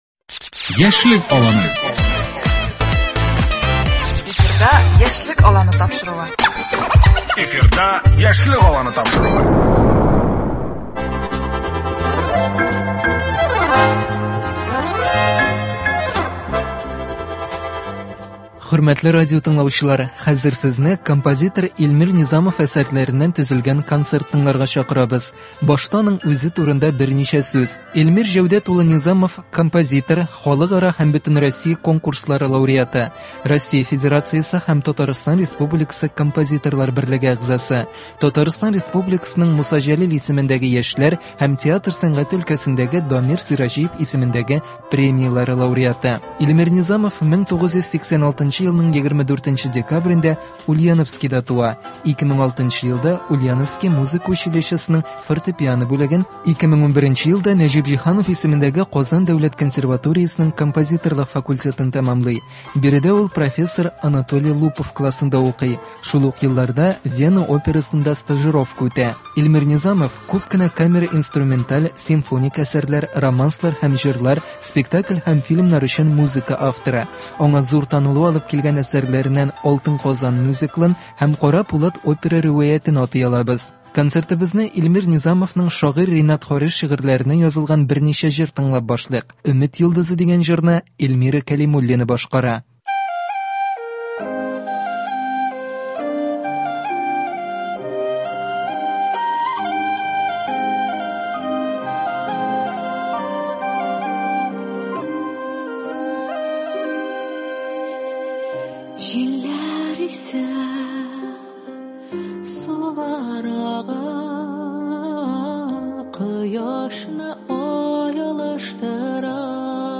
Яшь башкаручылар иҗаты.